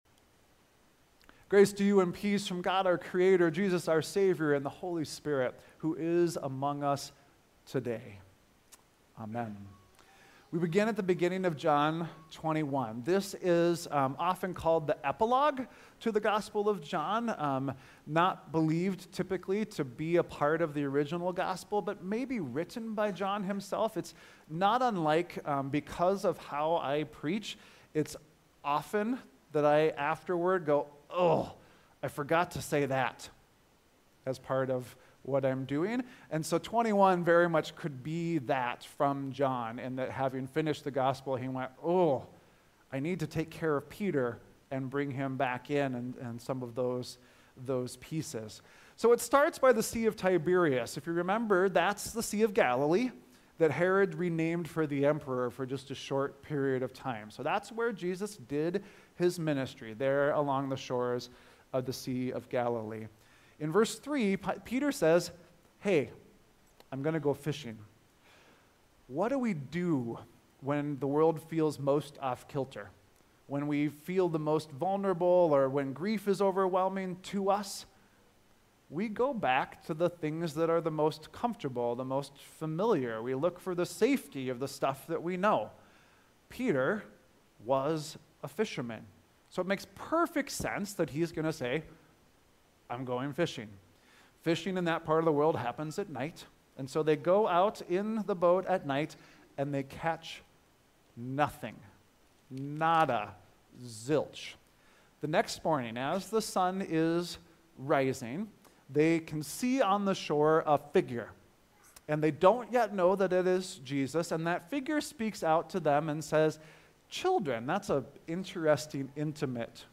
5.4.25-Sermon.mp3